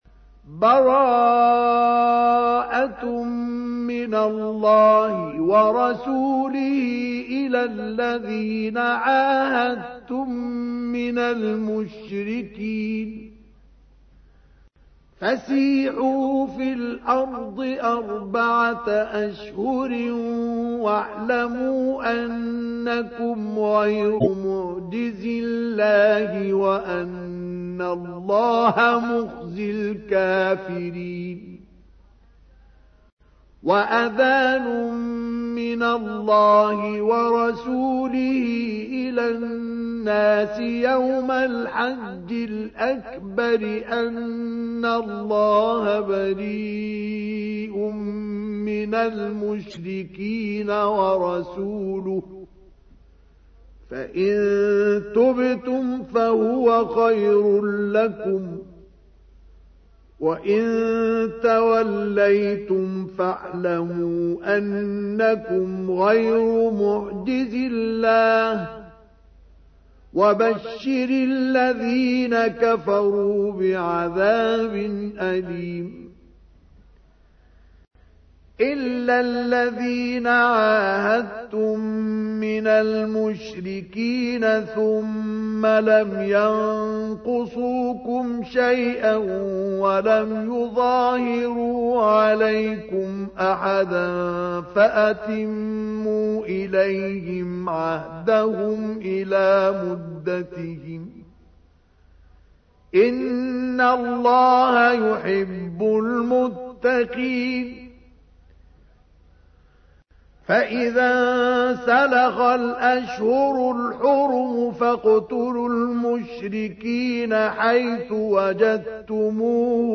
تحميل : 9. سورة التوبة / القارئ مصطفى اسماعيل / القرآن الكريم / موقع يا حسين